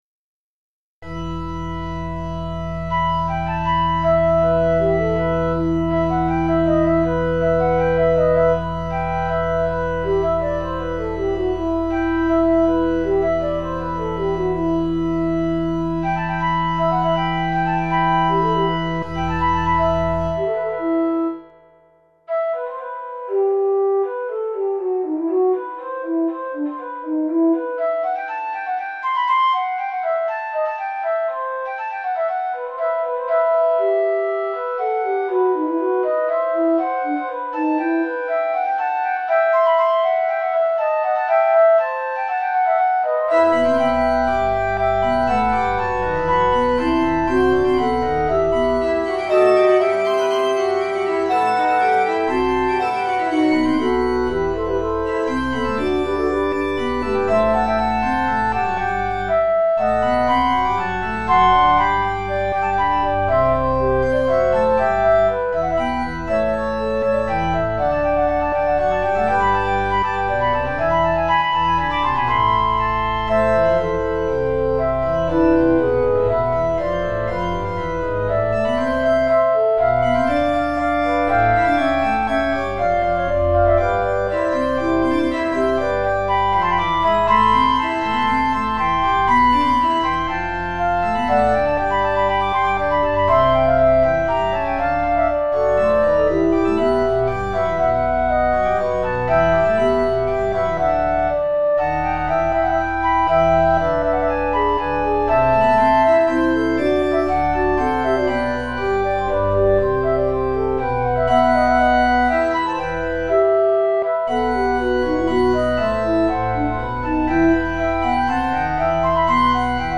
for Organ